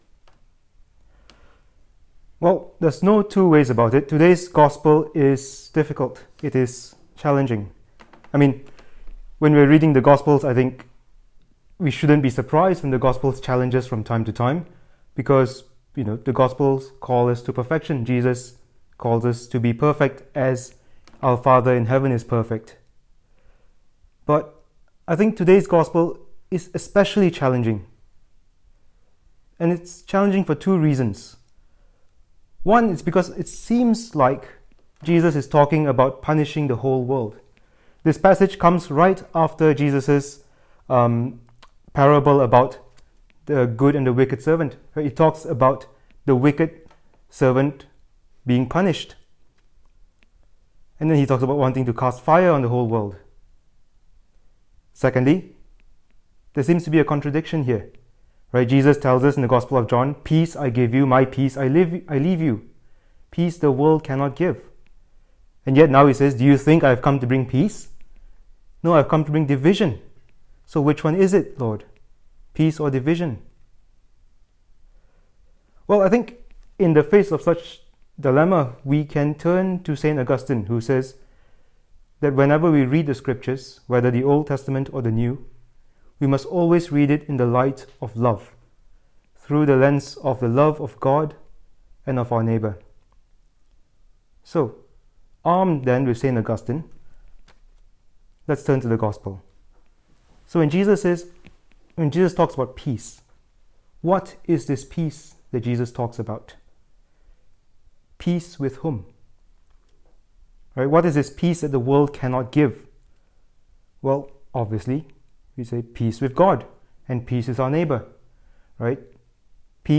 The following homily was preached to the student brothers during compline.